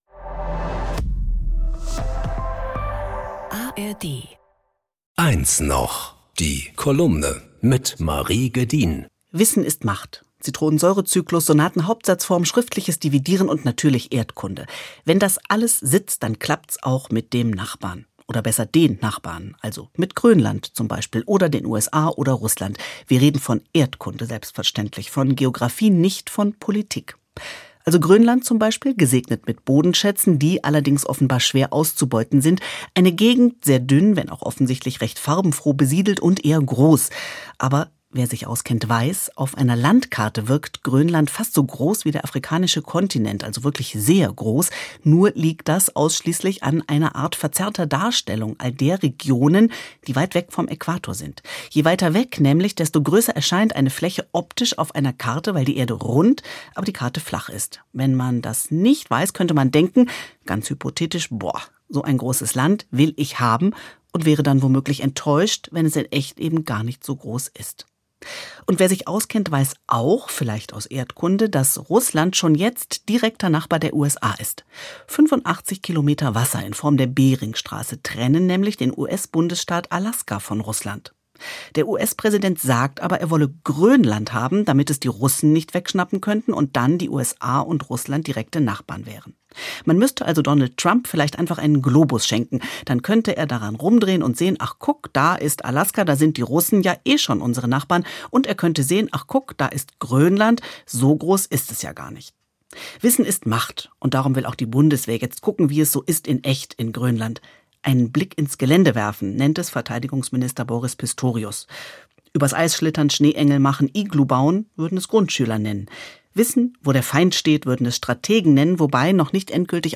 in ihrer Kolumne.